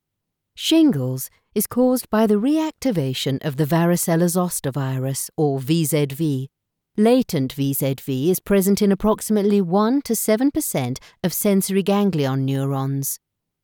Female
My voice is: Sensual, Calming, Engaging, Bright, Expressive, Intelligent, Authoritative
Medical Video Narration
0411Shingles_Medical_Assured.mp3